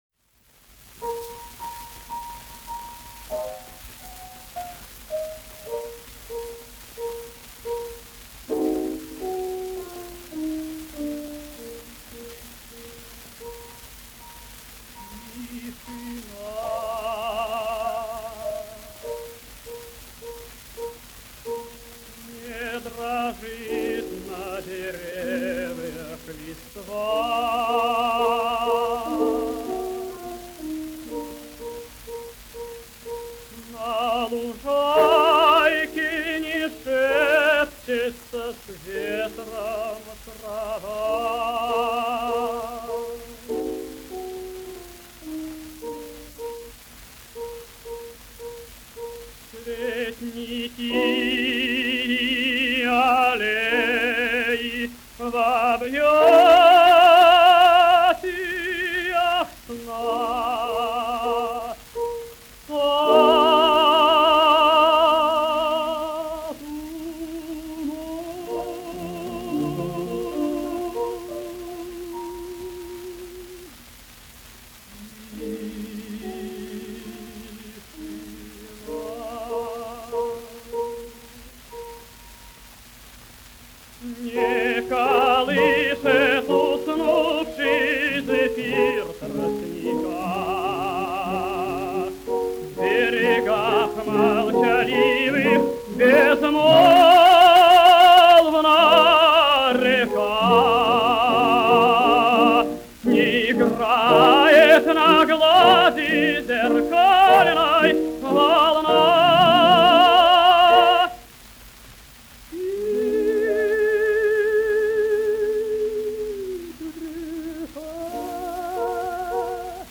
Арии из опер.